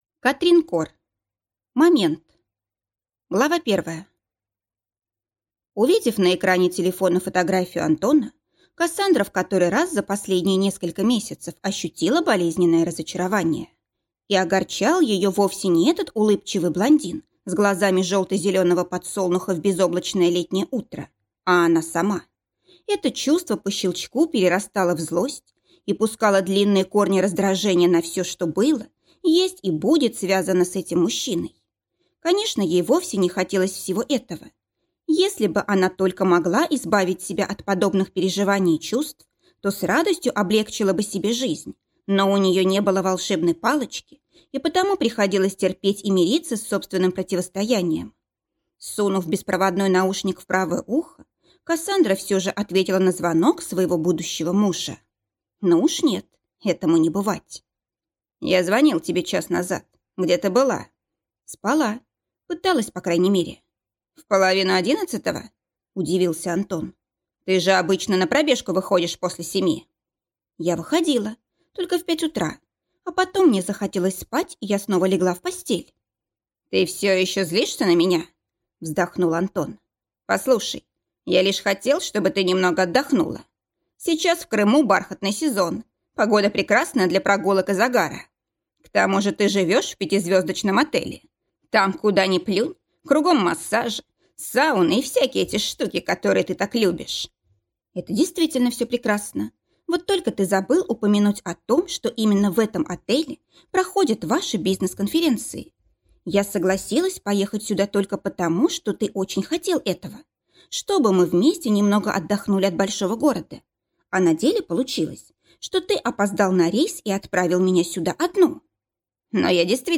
Аудиокнига Момент | Библиотека аудиокниг